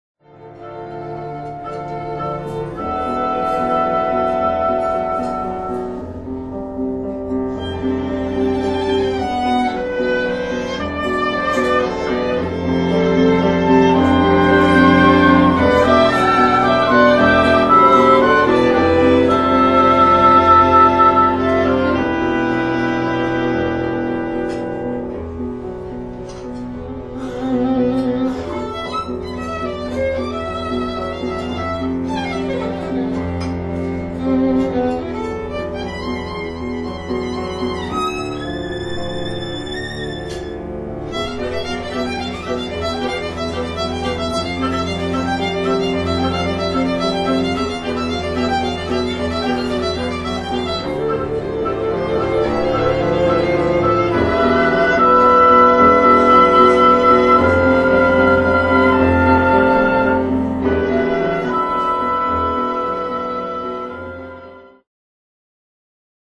Flute、Oboe、Clarinet、Violin、Cello、Piano